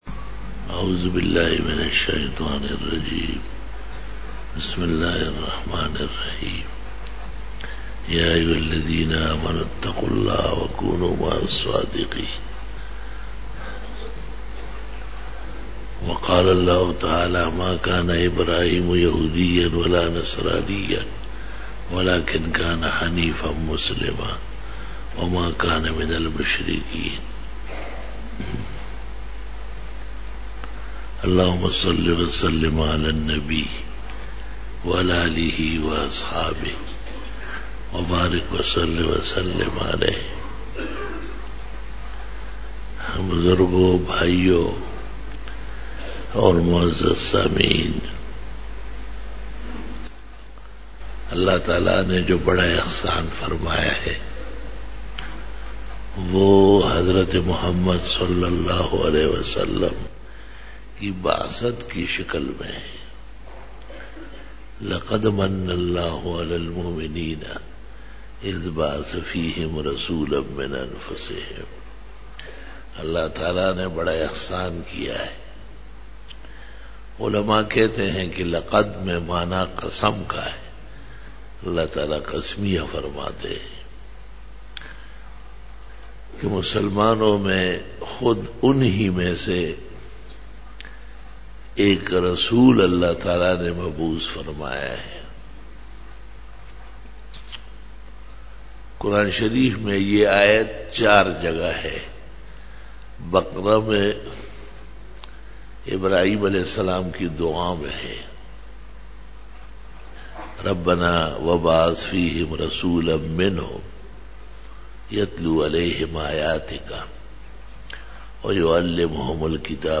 04 Bayan e juma tul mubarak 25-january-2013
بیان جمعۃ المبارک 25 جنوری 2013